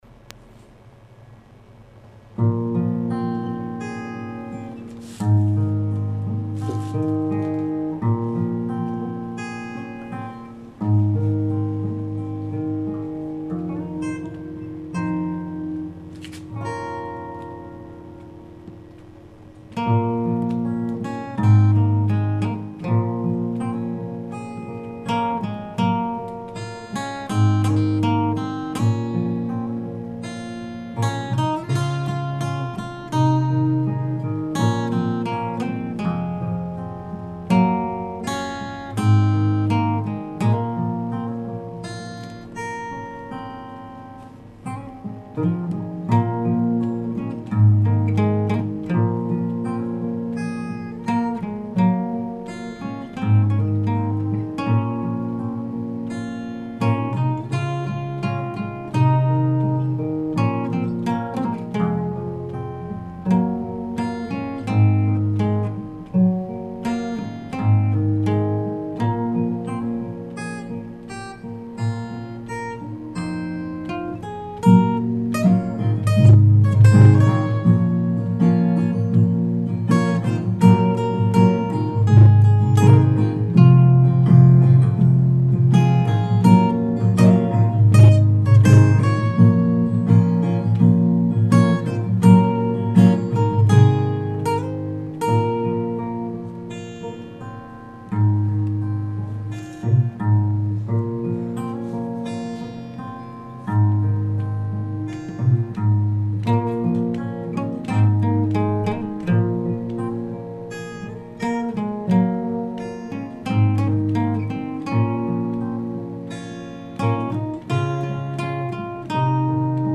[14/1/2009]吉他独奏